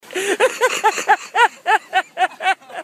laughing_real
Category: Comedians   Right: Personal